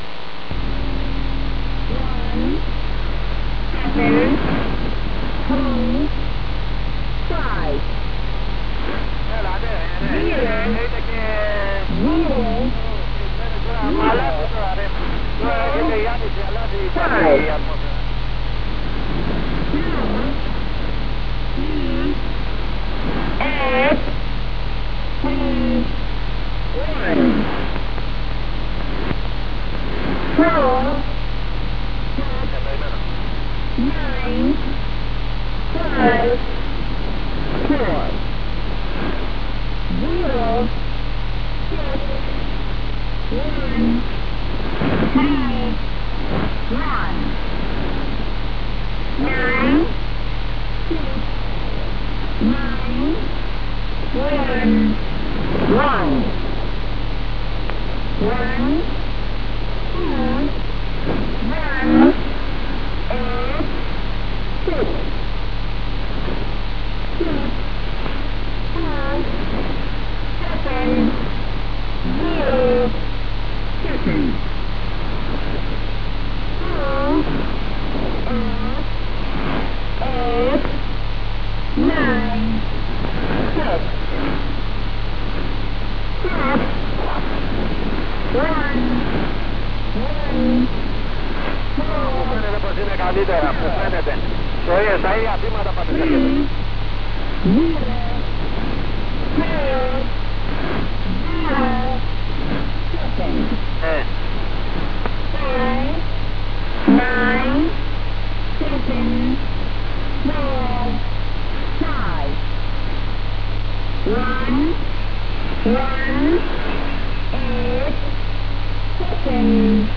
It opens and closes with a musical introduction from the album "Magnetic Fields" by Jean-Michel Jarre.
It appears to then send a message for 44D, spoken (in poor English) as "Fourty Four Dee".